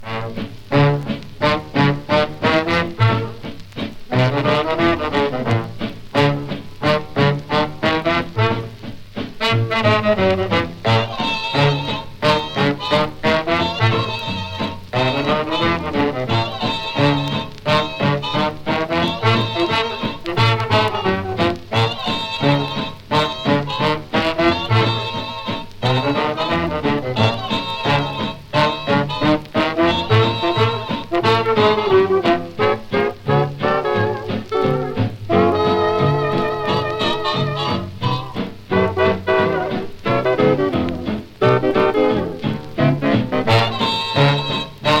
Jazz, Swing, Big Band　USA　12inchレコード　33rpm　Mono